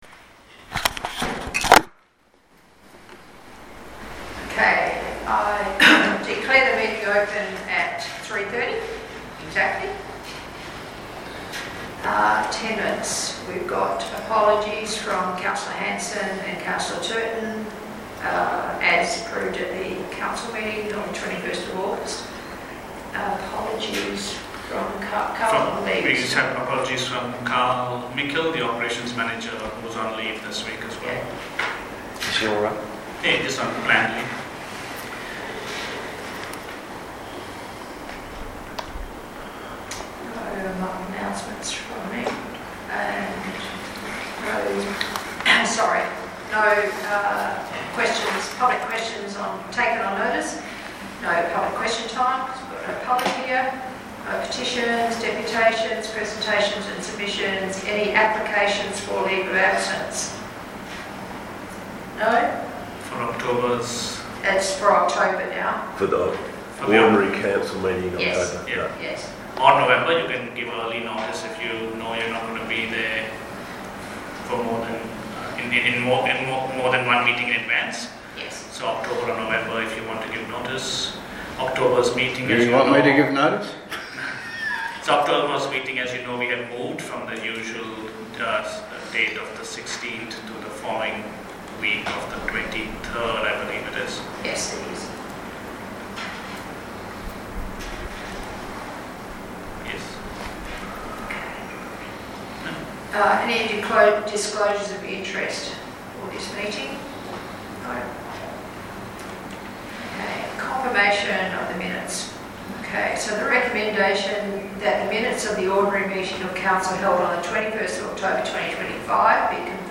recording-of-september-2025-ocm.mp3